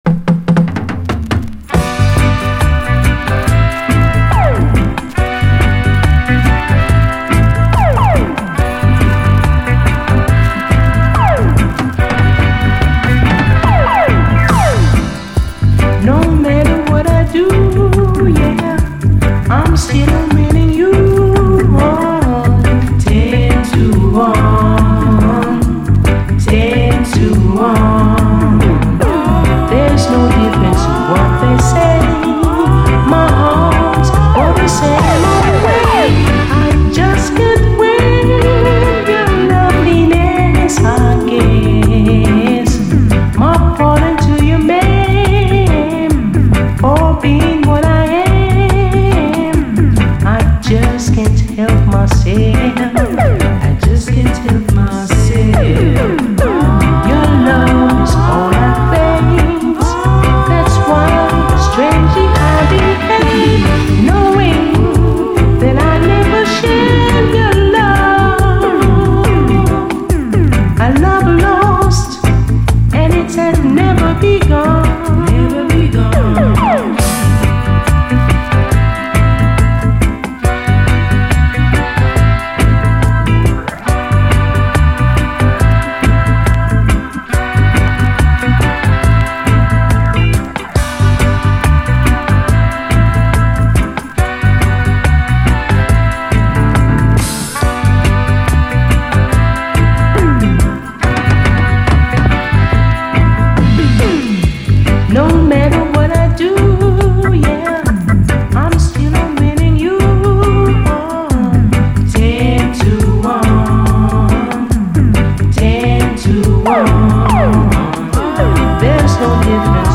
REGGAE
まろやかな歌声＆コーラスはそのままに、ピュンピュン音入りでダンサブルに仕上げたグレイト・ヴァージョン！後半はダブに接続。